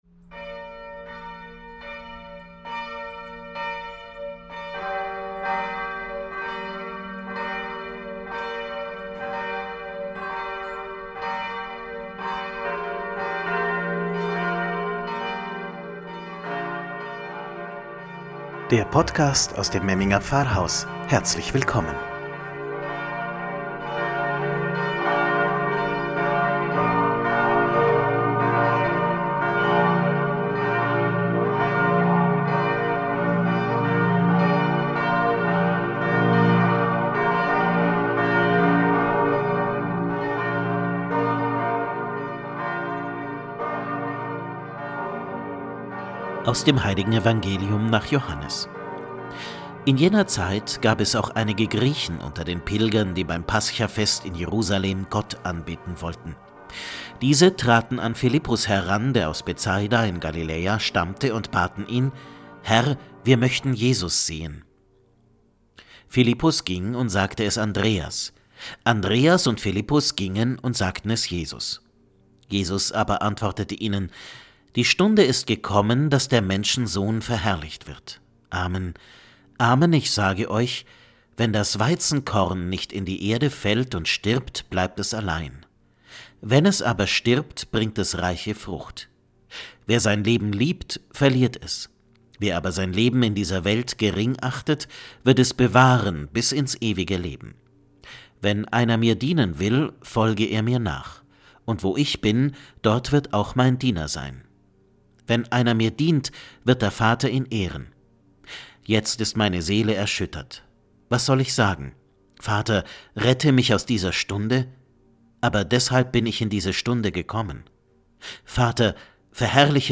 „Wort zum Sonntag“ aus dem Memminger Pfarrhaus – Fünfter Fastensonntag 2021